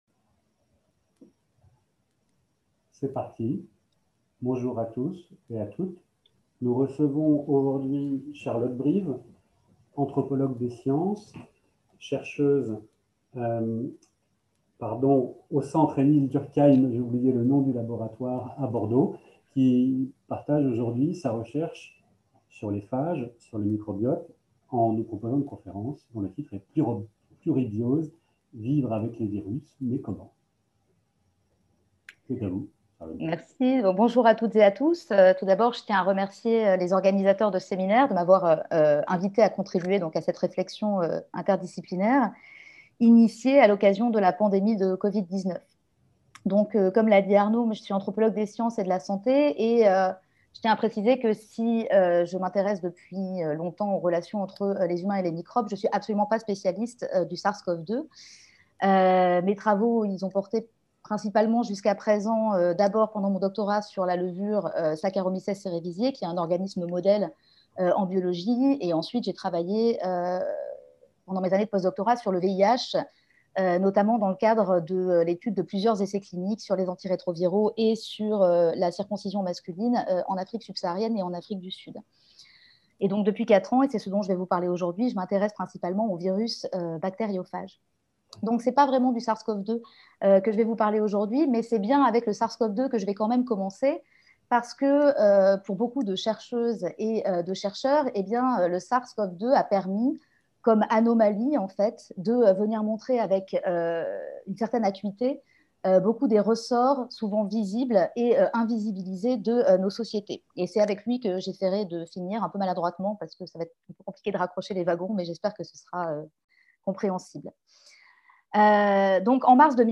interviendra dans le cadre du séminaire par visioconférence « Penser la pandémie ». En l’espace de quelques semaines, entre mars et mai 2020, le gouvernement est passé d’une rhétorique guerrière à l’idée d’une cohabitation forcée avec le Sars-Cov-2, sans pour autant changer d’orientation dans la façon d’envisager nos relations à ce virus.